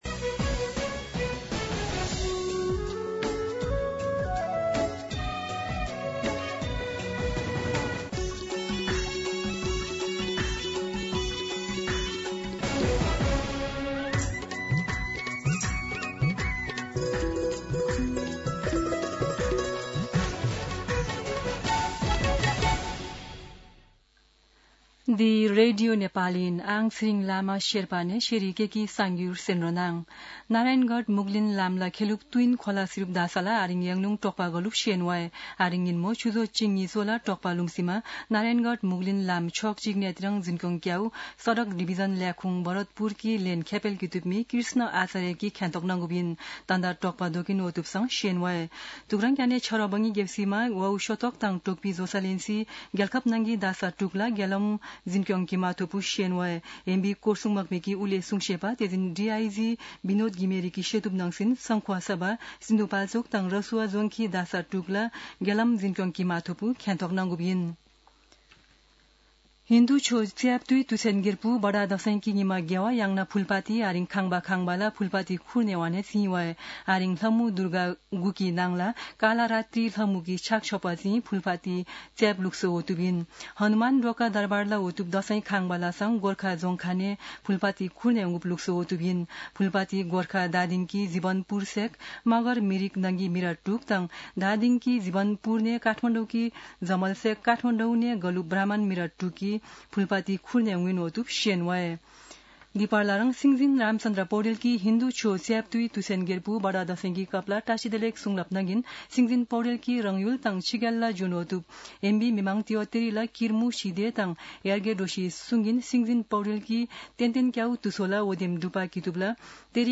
शेर्पा भाषाको समाचार : १३ असोज , २०८२
sherpa-News.mp3